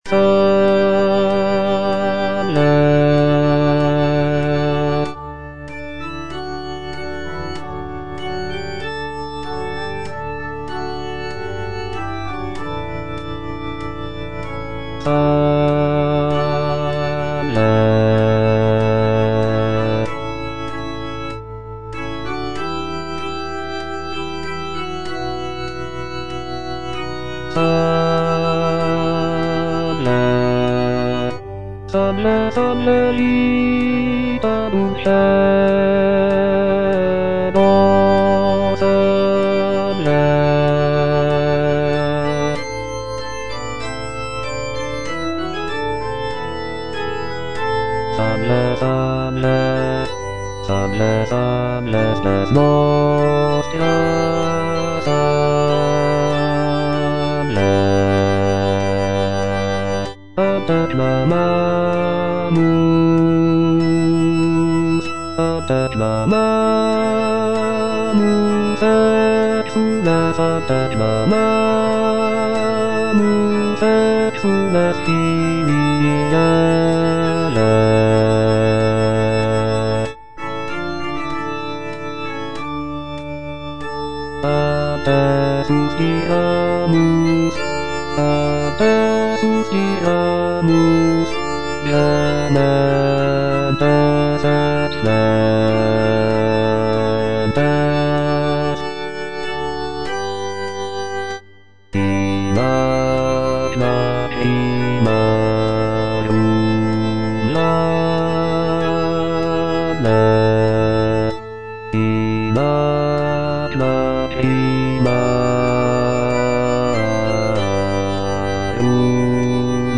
G.F. SANCES - SALVE, REGINA Bass (Voice with metronome) Ads stop: auto-stop
"Salve, Regina" by Giovanni Felice Sances is a sacred vocal work written in the 17th century.